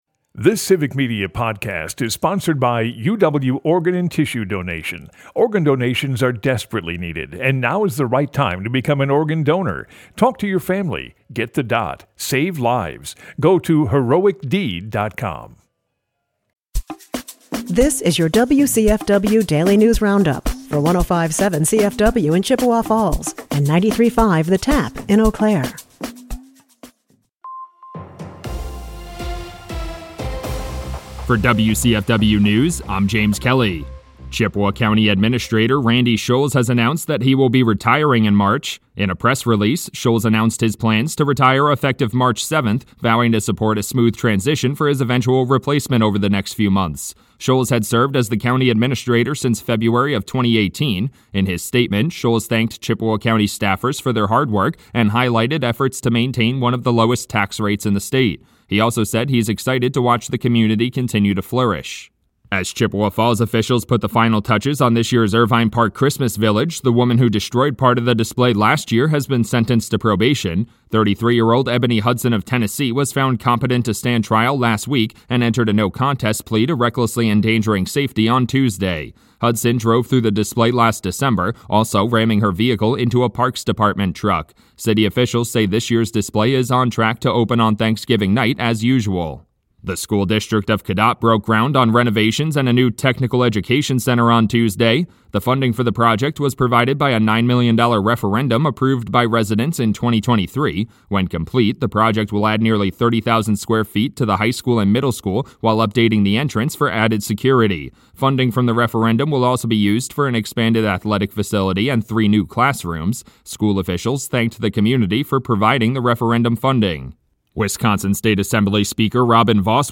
The WCFW Daily News Roundup has your state and local news, weather, and sports for Chippewa Falls, delivered as a podcast every weekday at 9 a.m. Stay on top of your local news and tune in to your community!